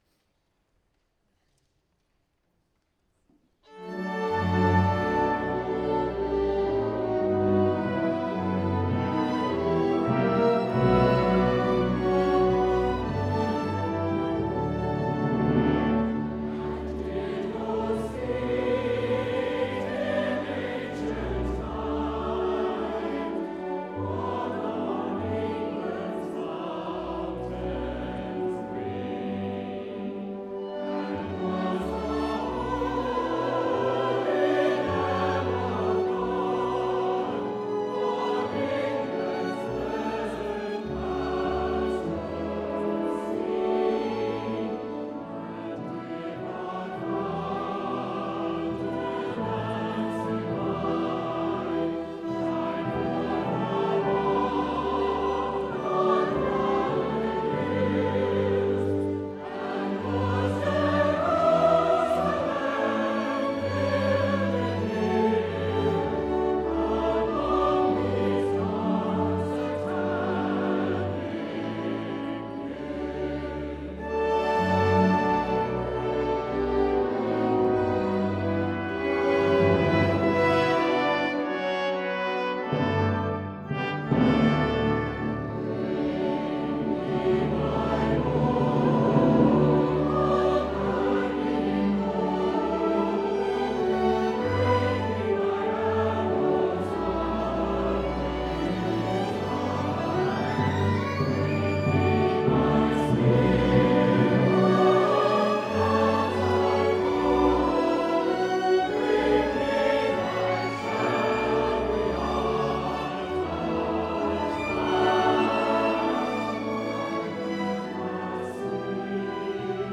April 24, 2016 Concert Sound